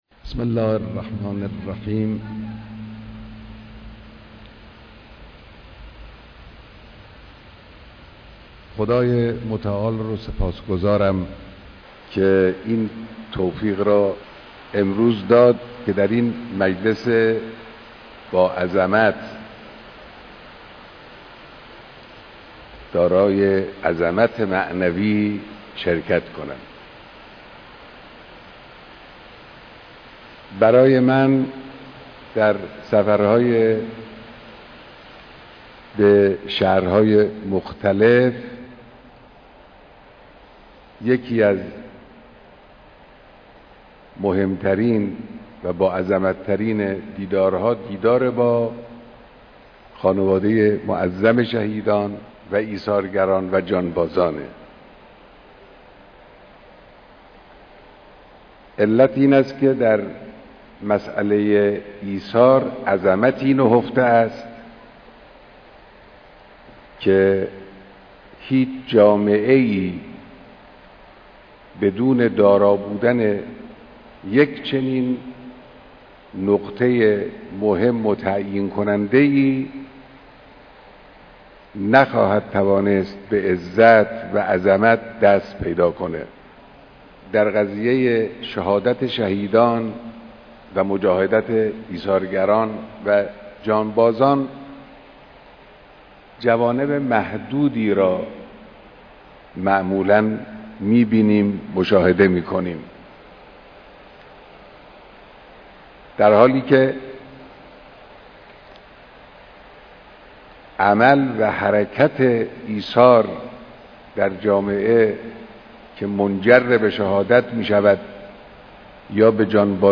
بیانات در جمع خانواده شهدا و ایثارگران استان کرمانشاه